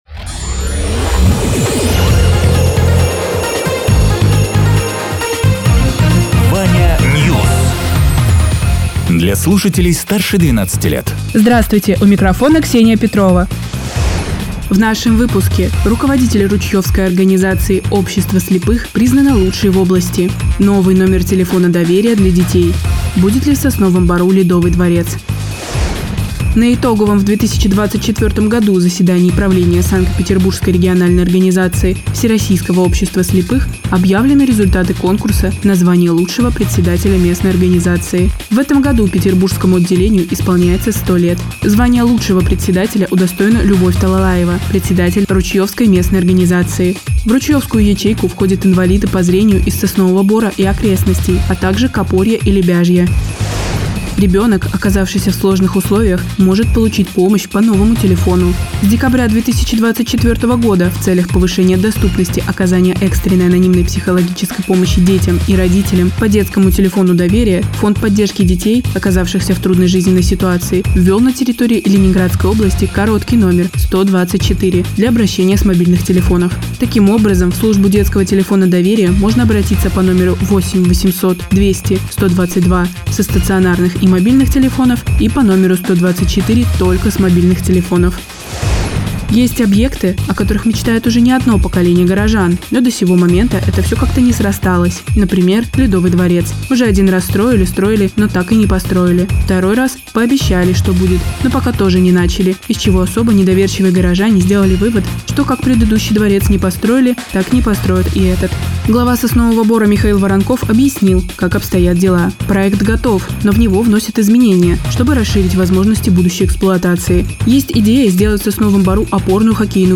Радио ТЕРА 27.12.2024_12.00_Новости_Соснового_Бора